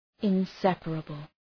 {ın’sepərəbəl}